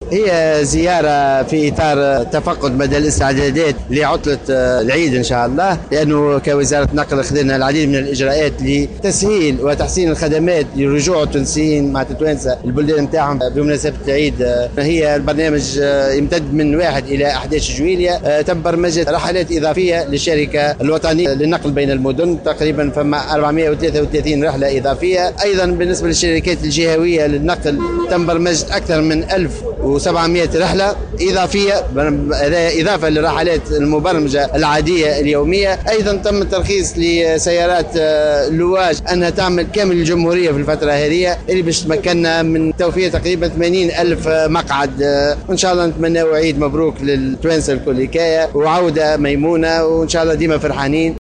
وقال في تصريحات صحفية على هامش زيارة قام بها لمحطة "باب عليوة" بتونس العاصمة، إنه تم تخصيص 433 رحلة إضافية للشركة الوطنية بين المدن وأيضا 1700 رحلة إضافية للشركات الجهوية للنقل بالإضافة إلى الترخيص لسيارات "اللواج" للعمل بكامل تراب الجمهورية خلال هذه الفترة.